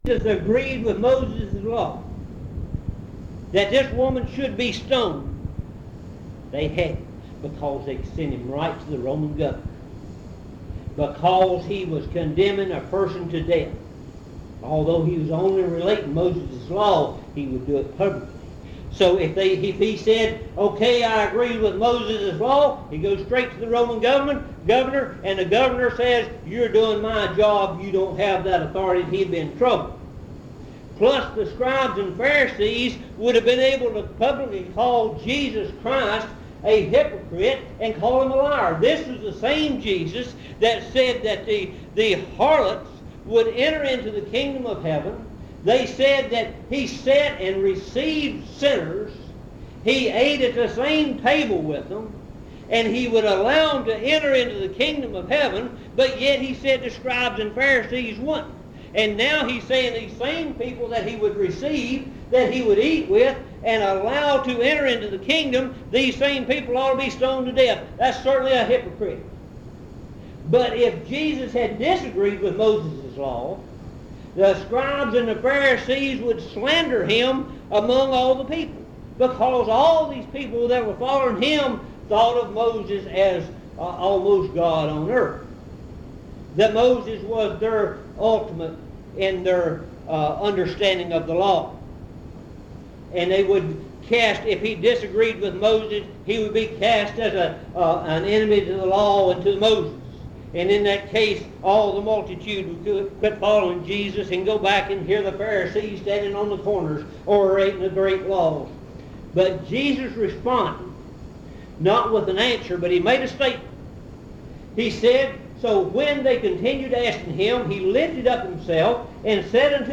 In Collection: Monticello Primitive Baptist Church audio recordings Miniaturansicht Titel Hochladedatum Sichtbarkeit Aktionen PBHLA-ACC.002_013-B-01.wav 2026-02-12 Herunterladen PBHLA-ACC.002_013-A-01.wav 2026-02-12 Herunterladen